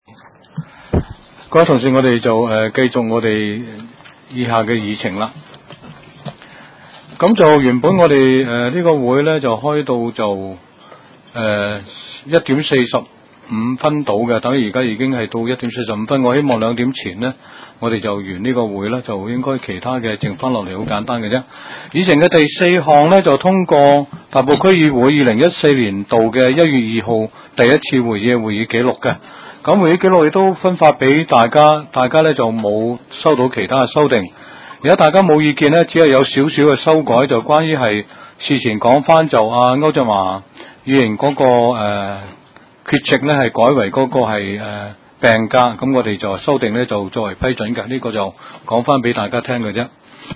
区议会大会的录音记录
大埔区议会秘书处会议室